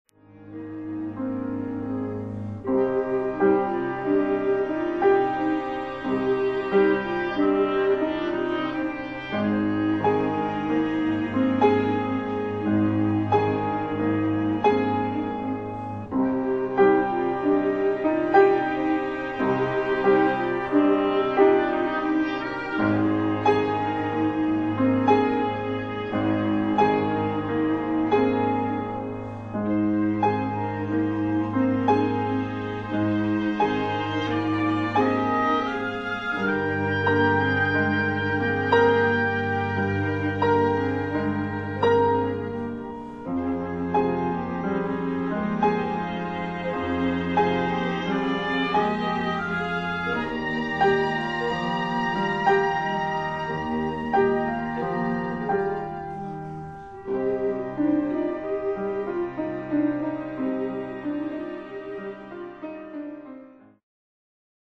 Flute、Oboe、Recorder、Viola、Cello、Piano